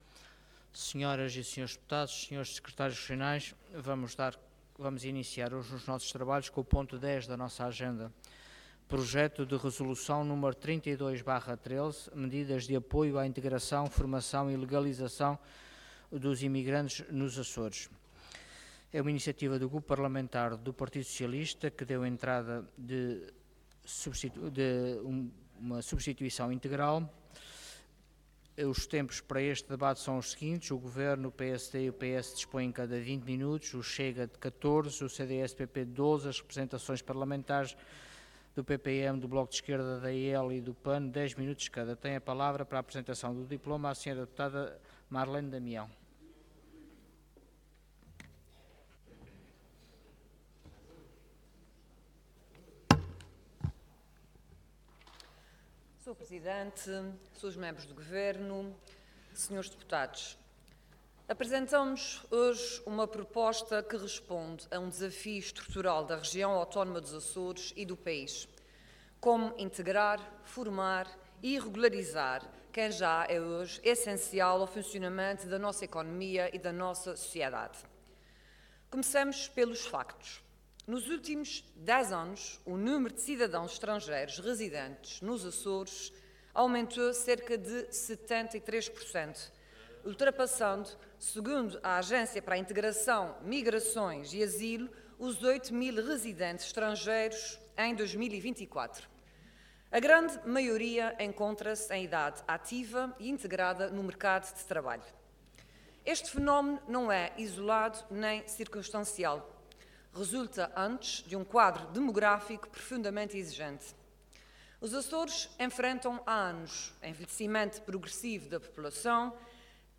Intervenção
Orador Marlene Damião Cargo Deputada Entidade PS